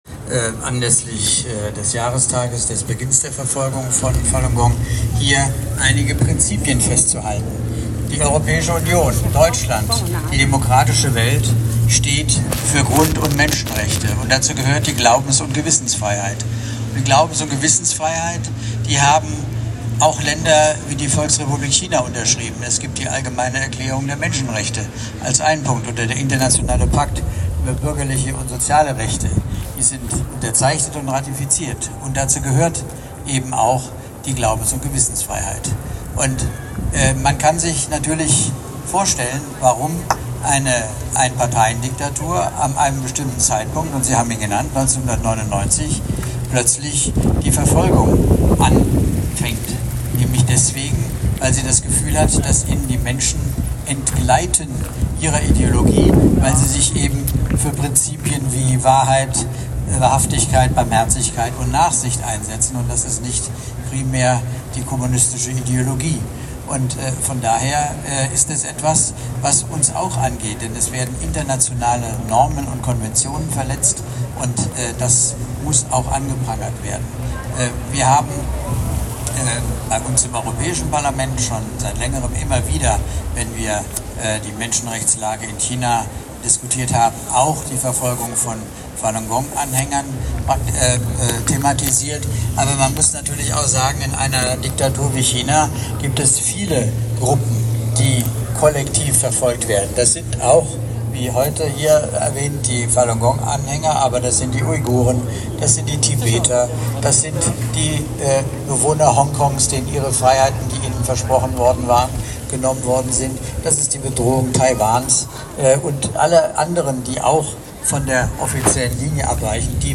Michael Gahler MEP addressed the Falun Gong rally in Frankfurt on July 19, 2025.